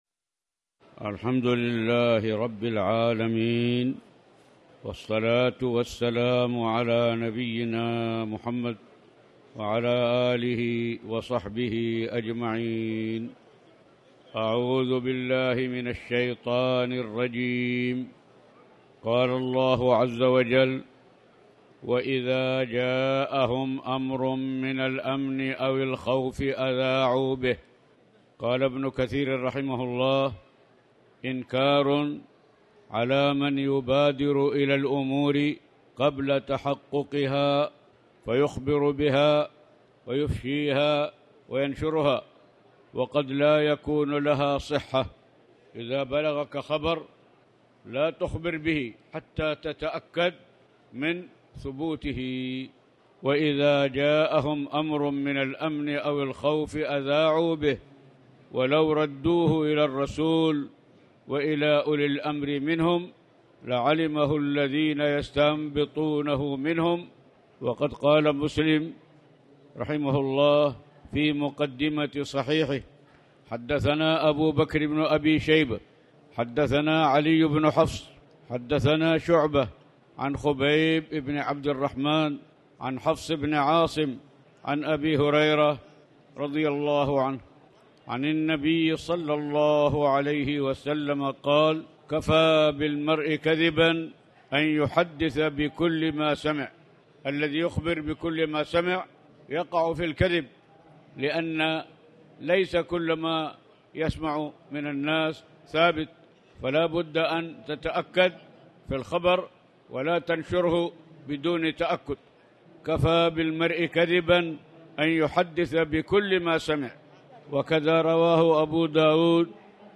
تاريخ النشر ١٣ ذو القعدة ١٤٣٨ هـ المكان: المسجد الحرام الشيخ